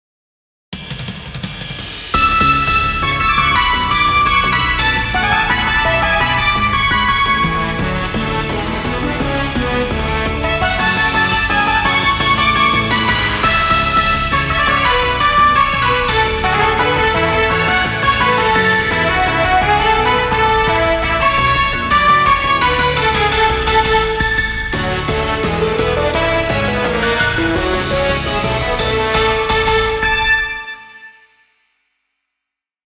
ダークが色濃いシリーズです。